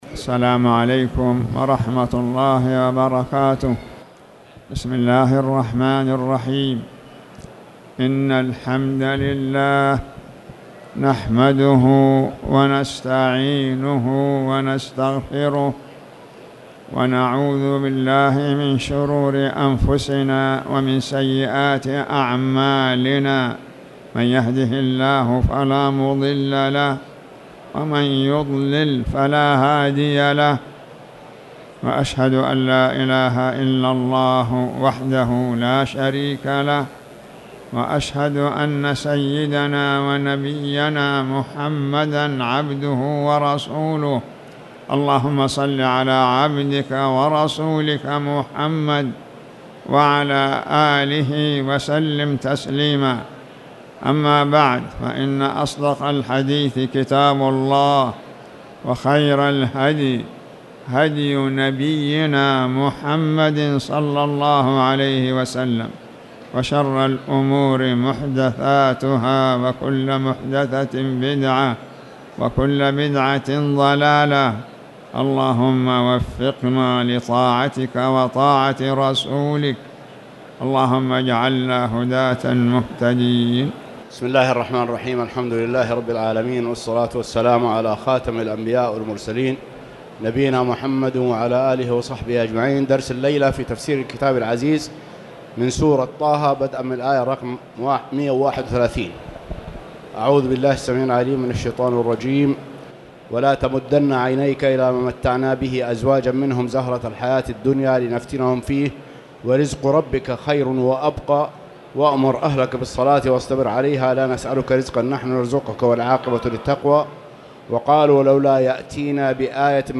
تاريخ النشر ٢٦ شعبان ١٤٣٨ هـ المكان: المسجد الحرام الشيخ